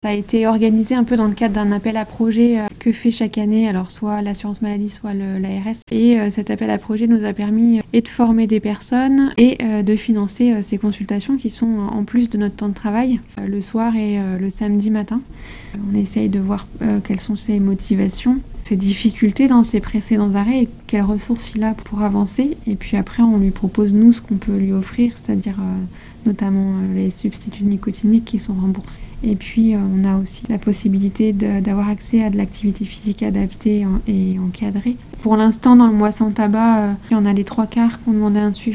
médecin de santé publique et tabacologue revient sur ces consultations gratuites pour les fumeurs